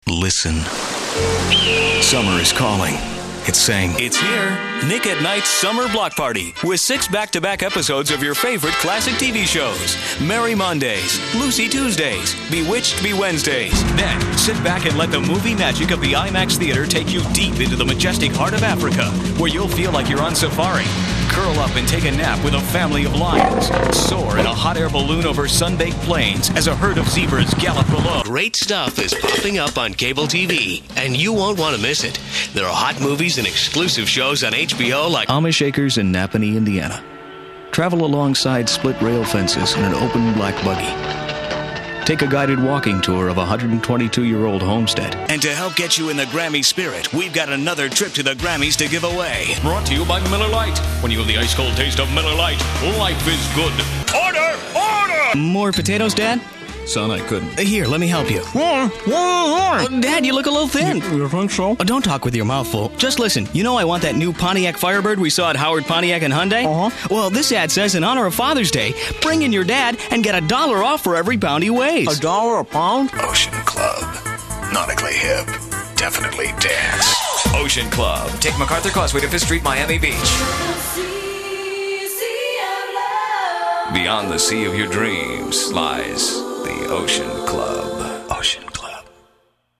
Audio clips demonstrating various voice-over styles from everyman to mellifluous rich announcer to excited engaging voice-overs.
Best of Copywriting, Voice & Production Demo
This demo showcases not only voice-over and production, but also creativity in copywriting and the ability to write across a wide range of clients, topics and industries.